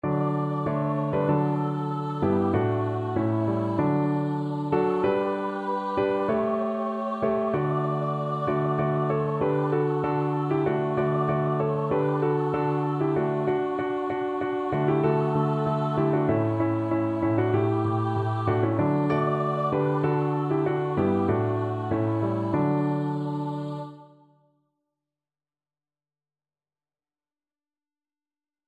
Free Sheet music for Choir (SA)
"Joy to the World" is a popular Christmas carol.
2/4 (View more 2/4 Music)
D major (Sounding Pitch) (View more D major Music for Choir )
Christmas (View more Christmas Choir Music)